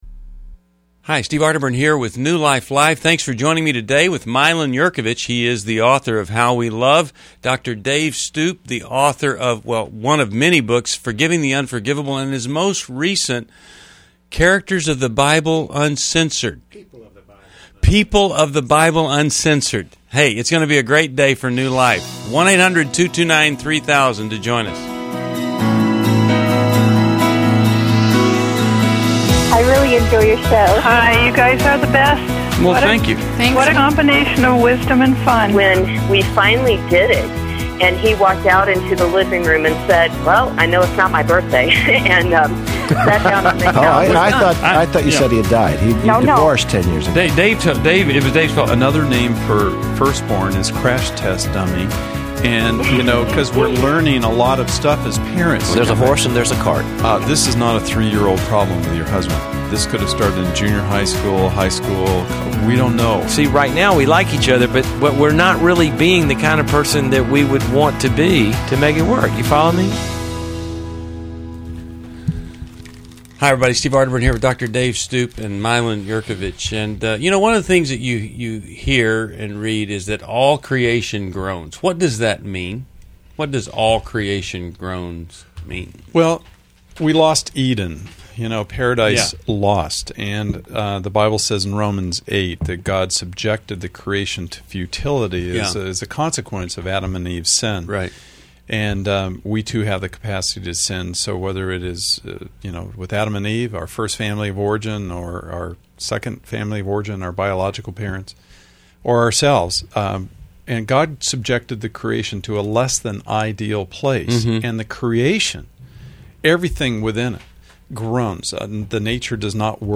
Join the discussion on anger management, forgiveness, and dating dilemmas in New Life Live: November 25, 2011, featuring insightful caller questions and expert advice.